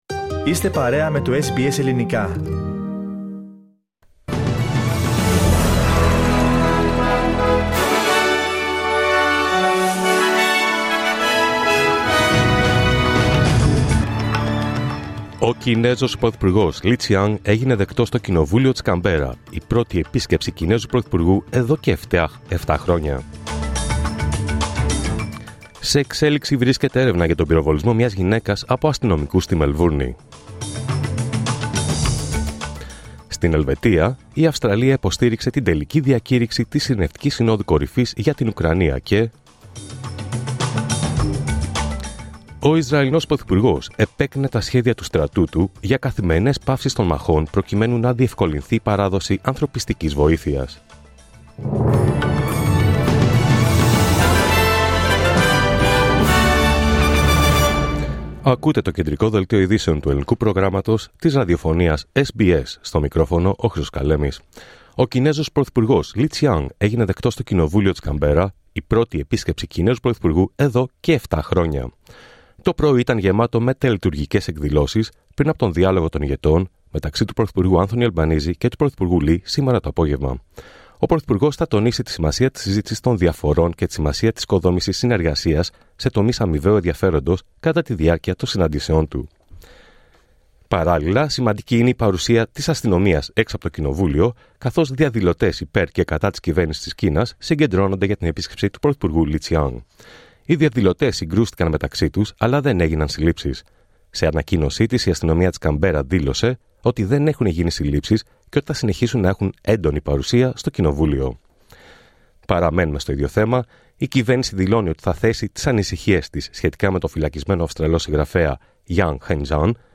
Δελτίο Ειδήσεων Δευτέρα 17 Ιουνίου 2024